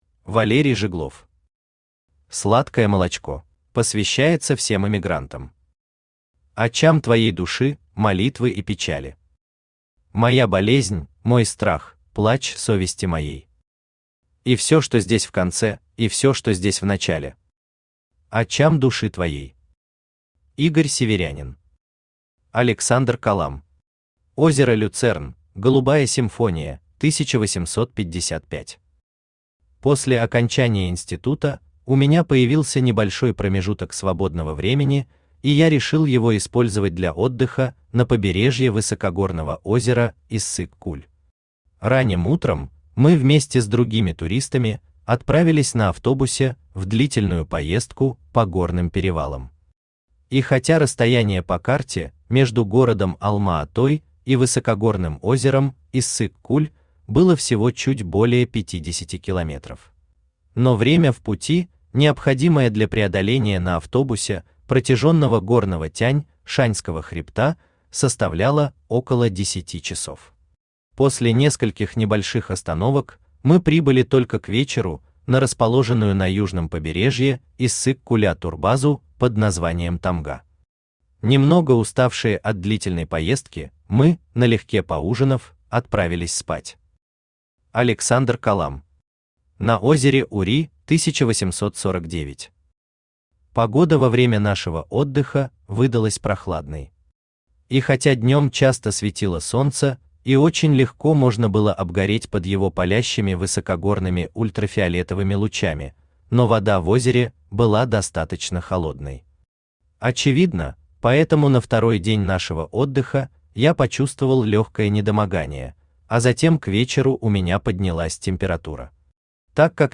Аудиокнига Сладкое молочко | Библиотека аудиокниг
Aудиокнига Сладкое молочко Автор Валерий Жиглов Читает аудиокнигу Авточтец ЛитРес.